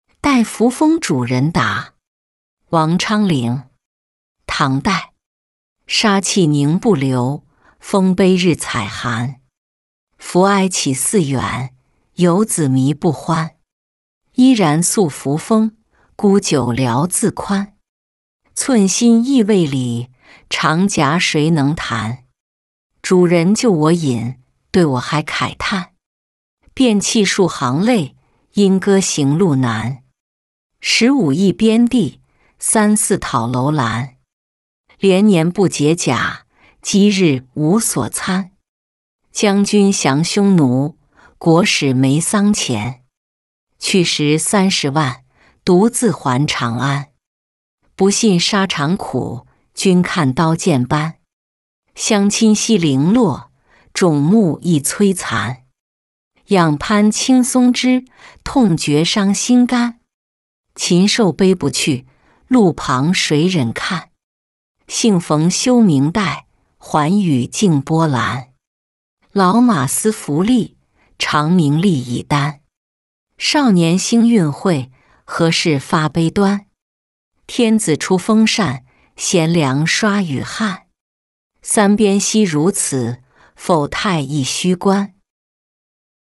代扶风主人答-音频朗读